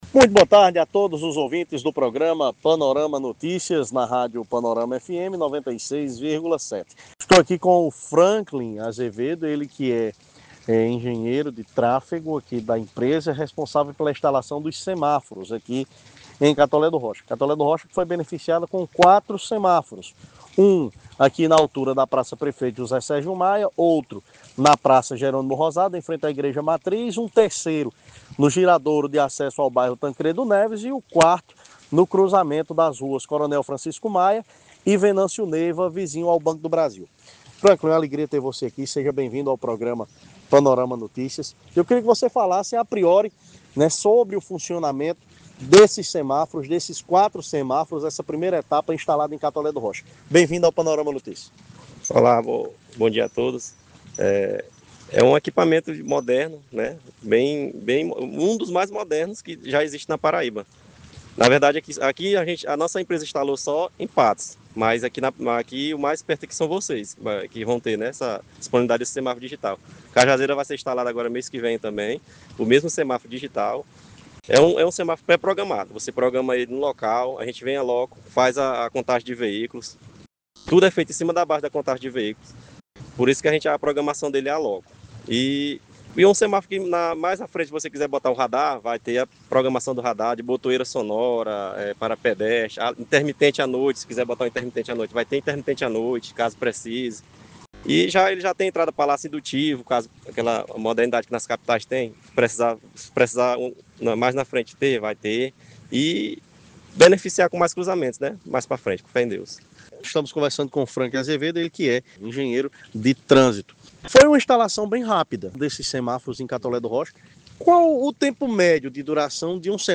Na tarde desta terça-feira (20), no Programa Panorama Notícias